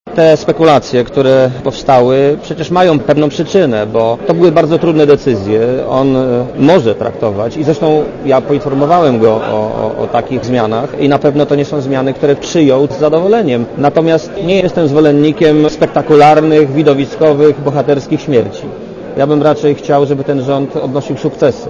Posłuchaj premiera Marka Belkę O ewentualnej dymisji Hausnera napisało piątkowe "Życie Warszawy", powołując się na jego bliskiego współpracownika.